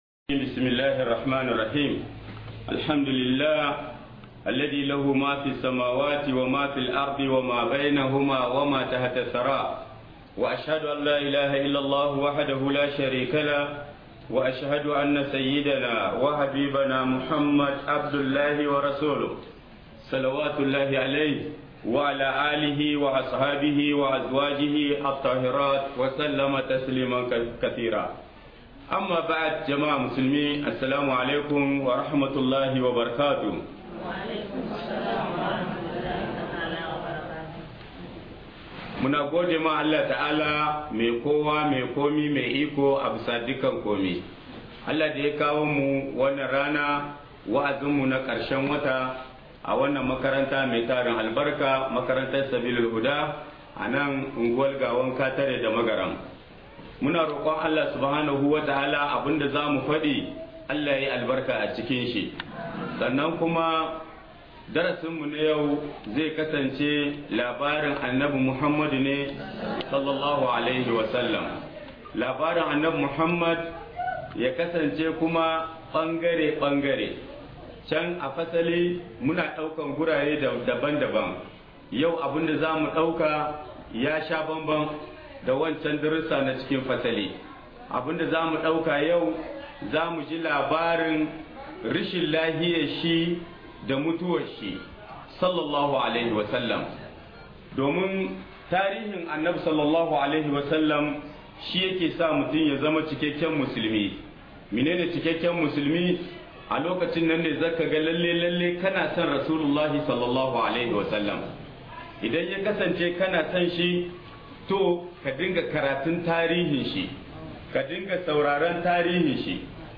RACHIN_LAFIYAR_ANNABI_S_A_W_DA_MUTUWAR - Muhadara